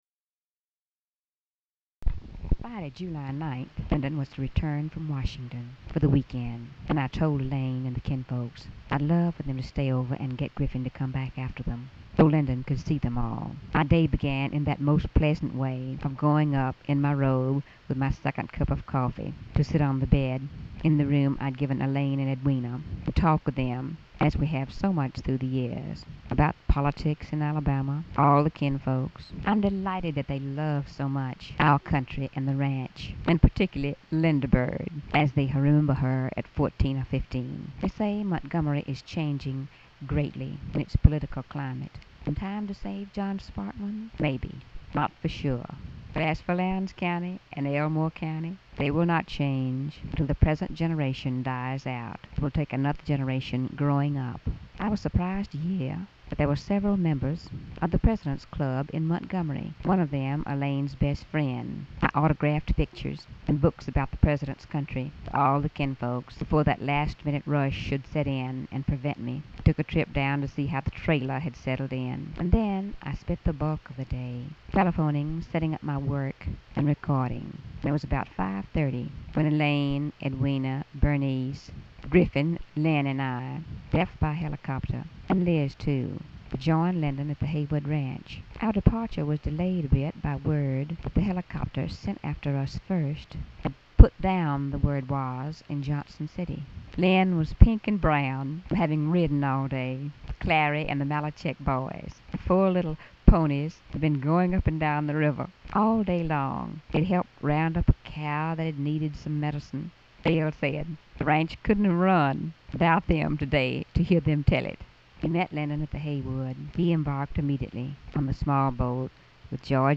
Audio diary and annotated transcript, Lady Bird Johnson, 7/9/1965 (Friday) | Discover LBJ
Sound Recordings of Lady Bird Johnson's Diary